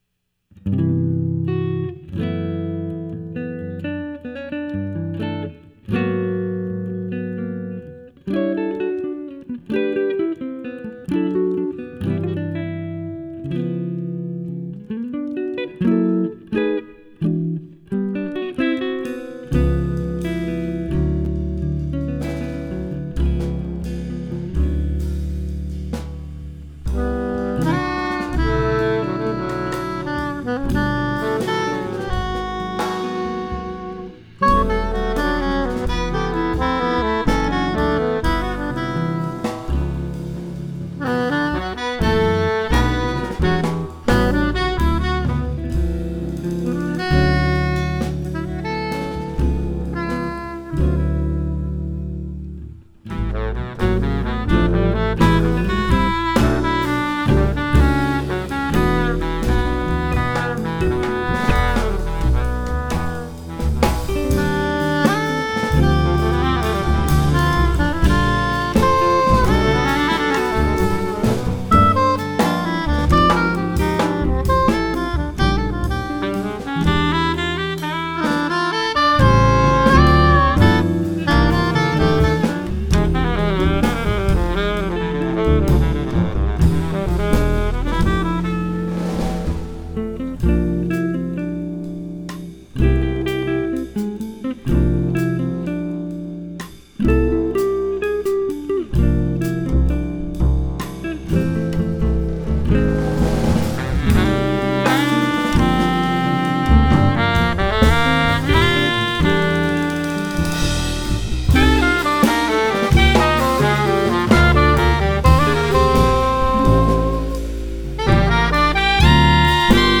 jazz guitarist